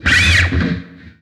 Track 02 - Guitar Neck Slide OS.wav